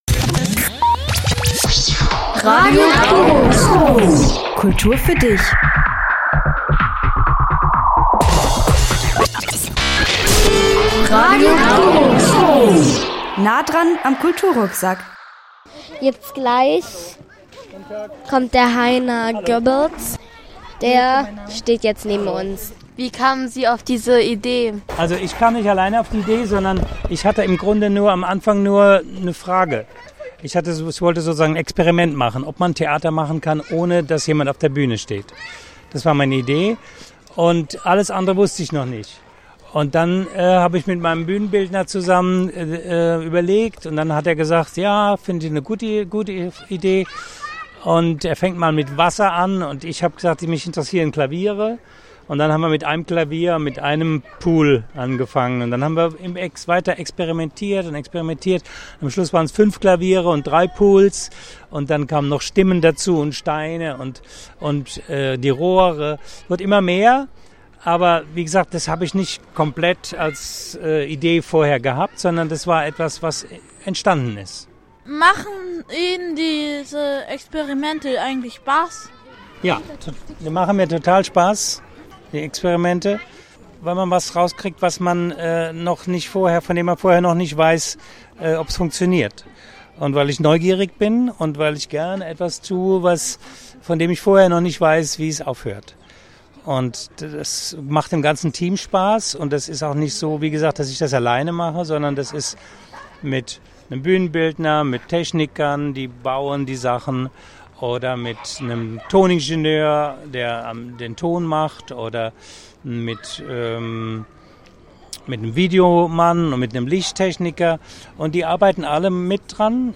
Ruhrtriennale 2 Interview Heiner Goebbels
Ruhrtriennale-2-Interview-Heiner-Goebbels.mp3